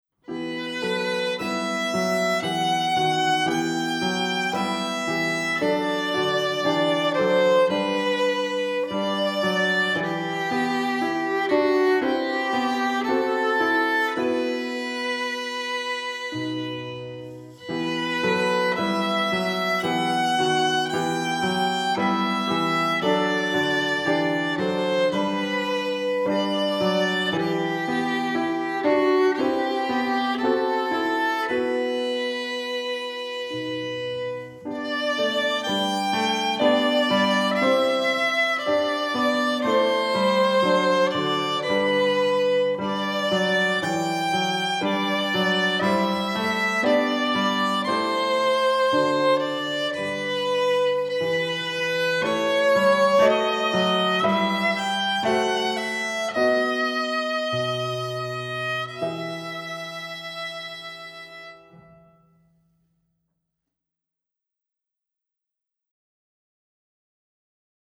Alcím Könnyű előadási darabok ifjú hegedűsöknek